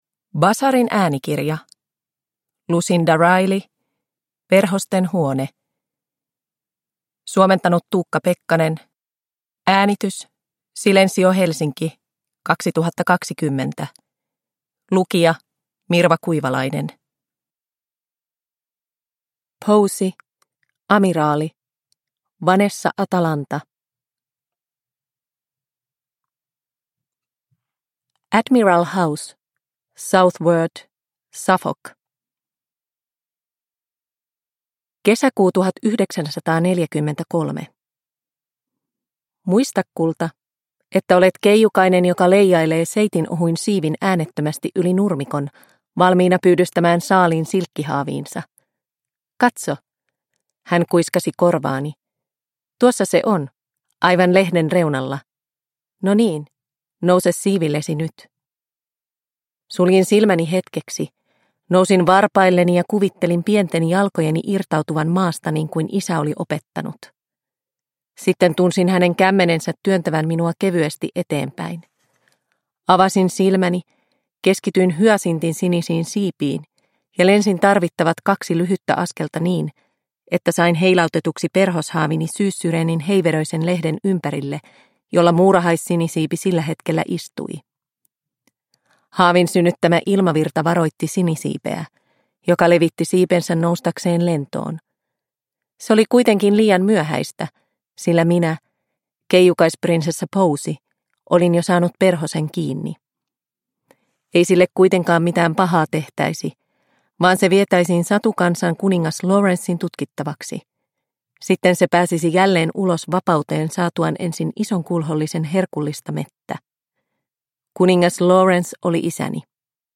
Perhosten huone – Ljudbok – Laddas ner